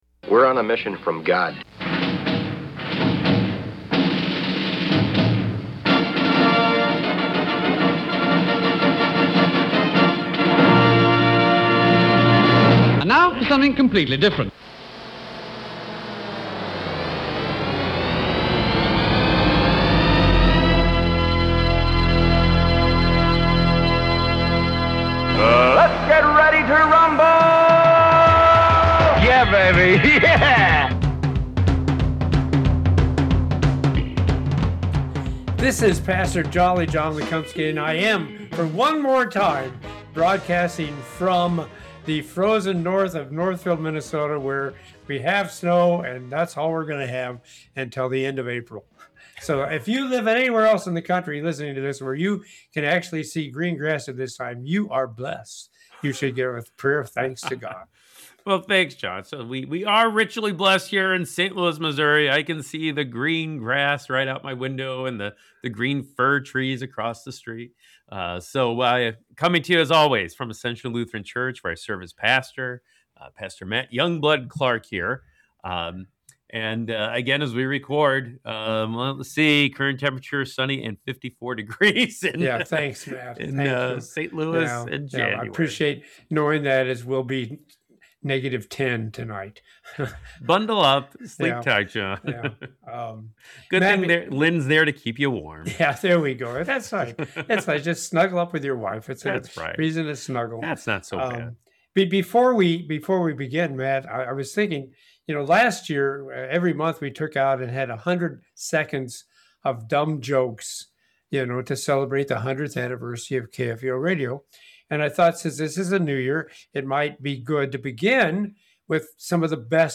With hosts